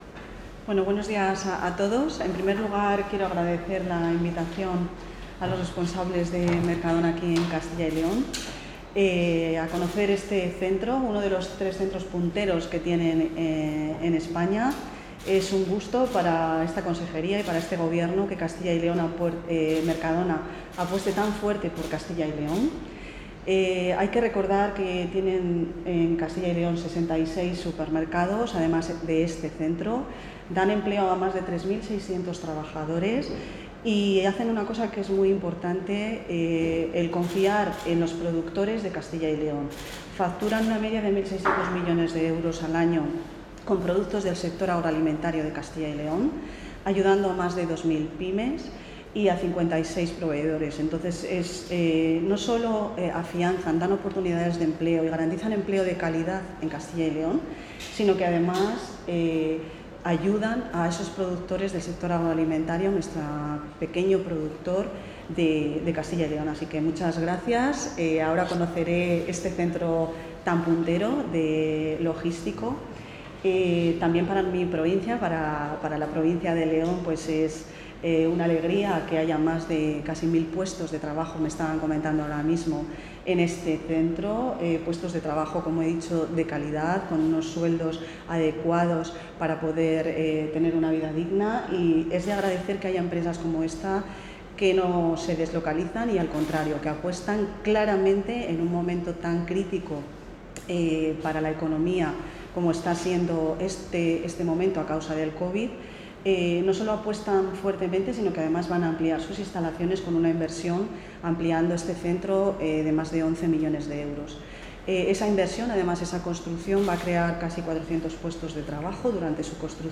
Material audiovisual de la visita de la consejera de Empleo e Industria al Bloque Logístico de Mercadona
Declaraciones de la consejera de Empleo e Industria.